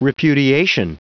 Prononciation du mot repudiation en anglais (fichier audio)
Prononciation du mot : repudiation